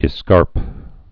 (ĭ-skärp)